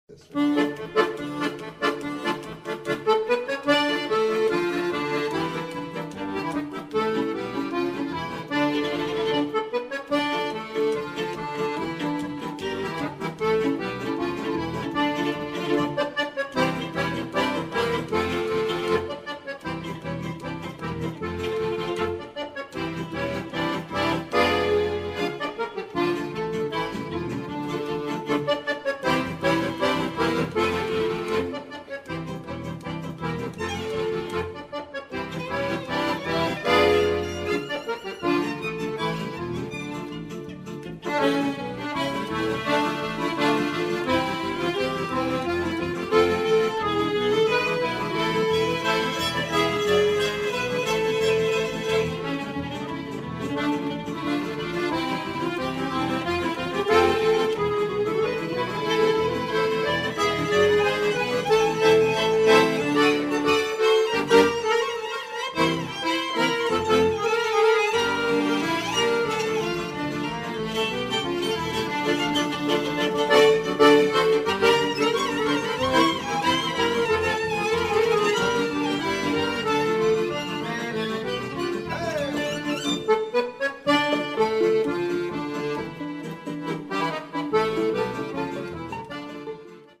Minneapolis Accordionist 1
As a solo instrument, the accordion works well acoustically for strolling, or by amplifying and incorporating midi electronics, his accordion can be made to sound like a one-man ensemble.